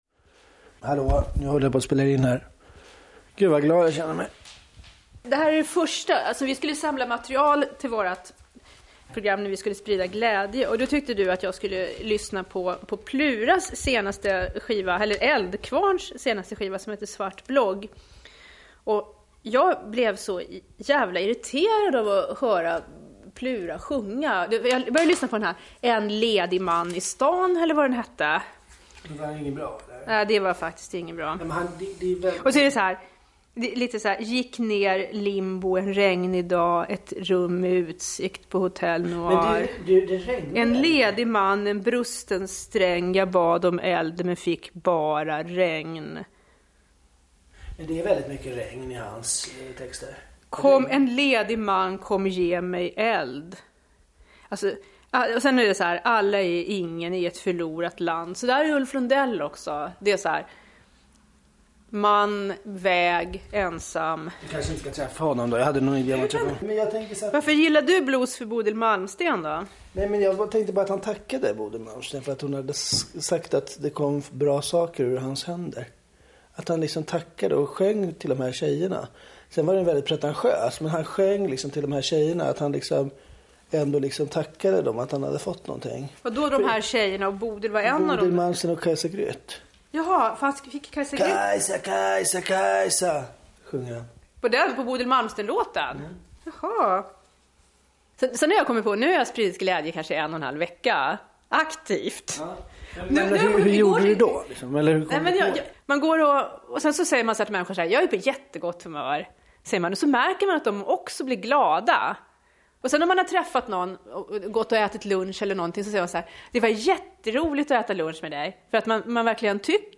Kulturdokumentär i P1. https